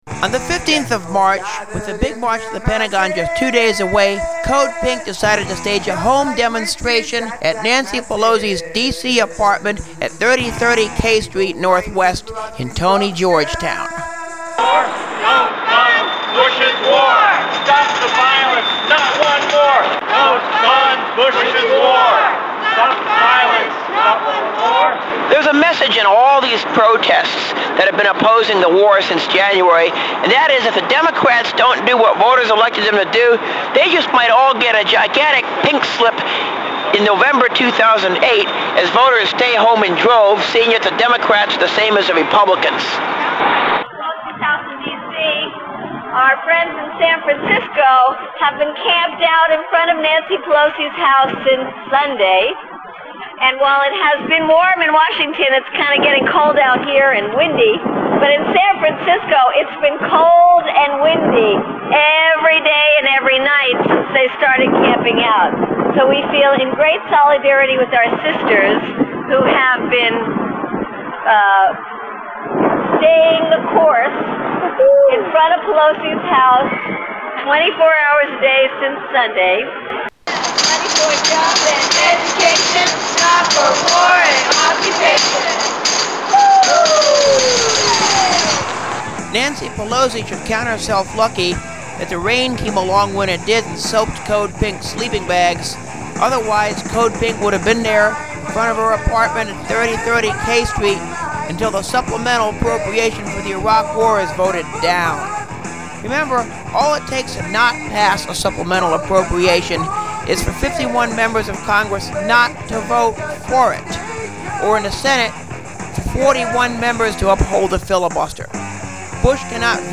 Audio for this segment is from INSIDE the police wagon,courtesy of Pacifica Radio, as part of the Nancy Pelosi audio piece.
code_pink_at_pelosis_apt_m15.mp3